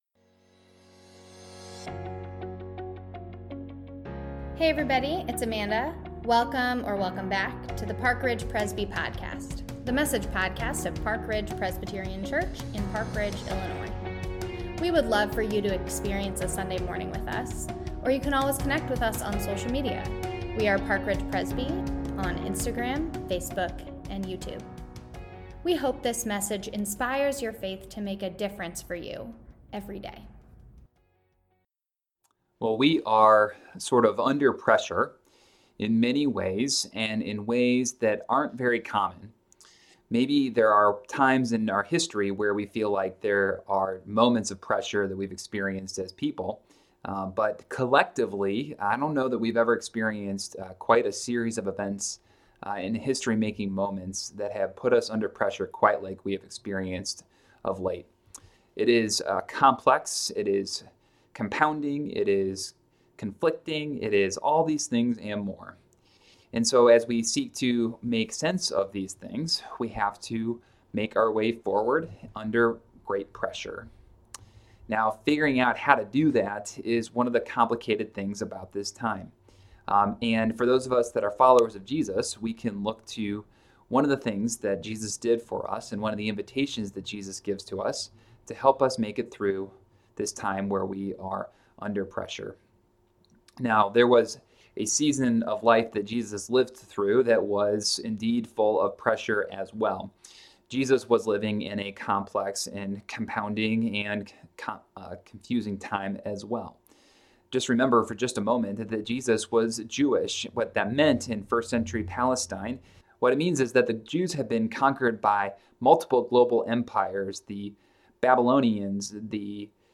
World Communion Sunday